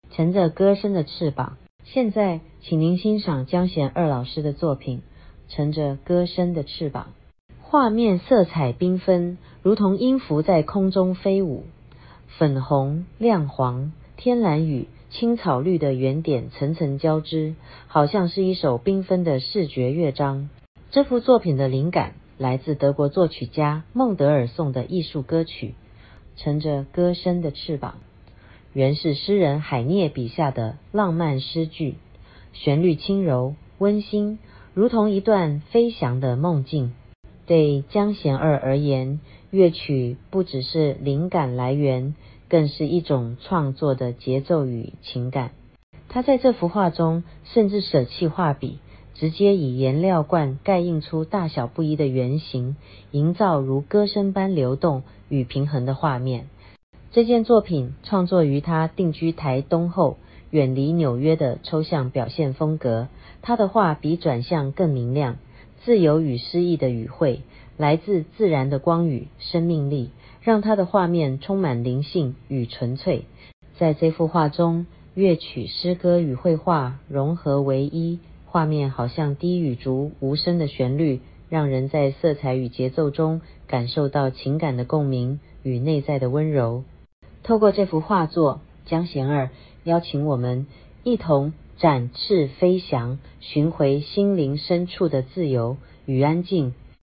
中文語音導覽：